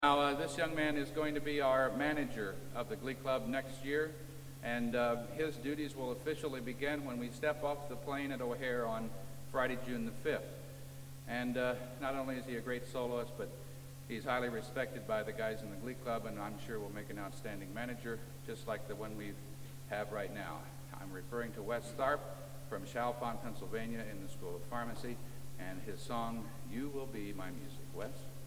Location: Old Academy of Music, Stockholm, Sweden
Genre: | Type: Director intros, emceeing